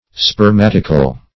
Spermatical \Sper"mat"ic*al\, a.